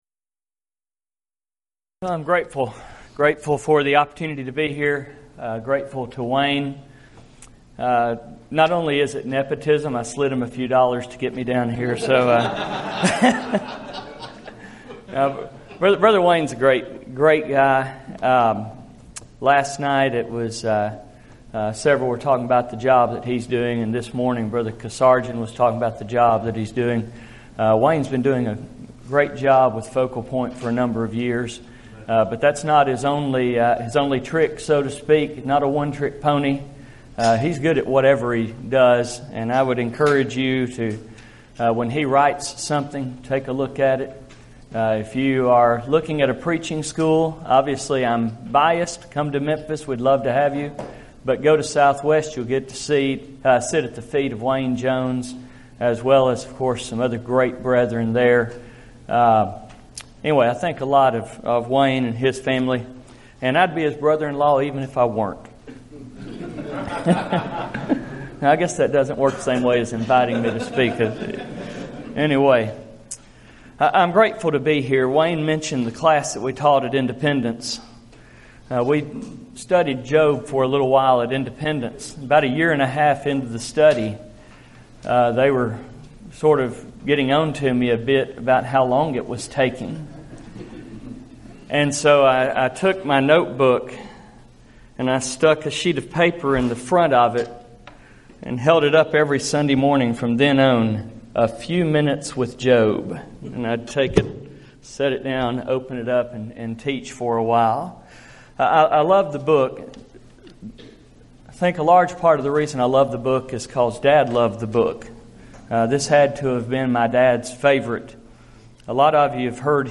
Event: 2015 Focal Point Theme/Title: Preacher's Workshop
lecture